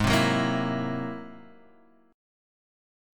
Listen to Ab+9 strummed